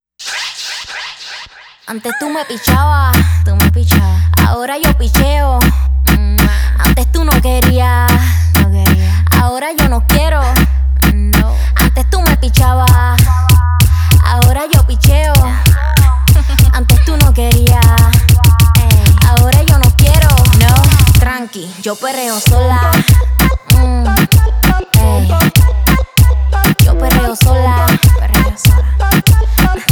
• Urbano latino